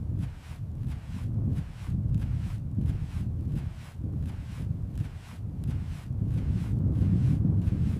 Boots crunch on sunbaked stone, a steady rhythm. On his back, the device hums—a quiet promise of endless, clean water. He pauses, breathes in the vast silence, truly at peace.